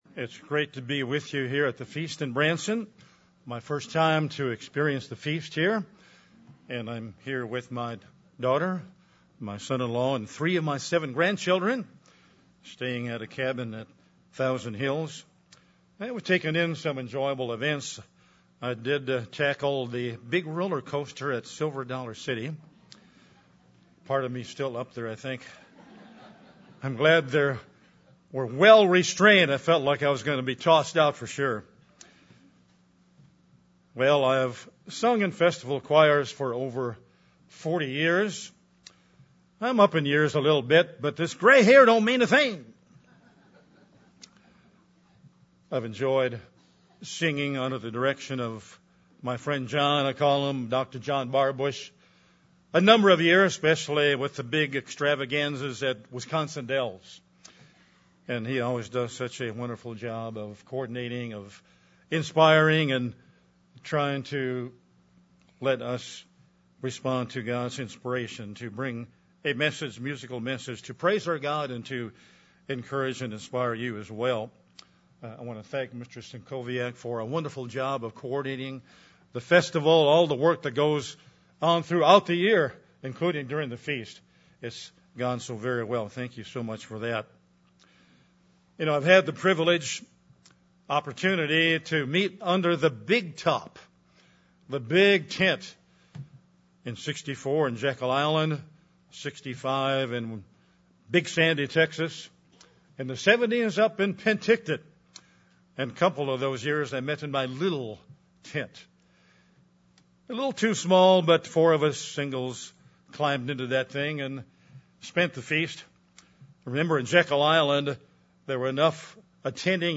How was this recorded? This sermon was given at the Branson, Missouri 2015 Feast site.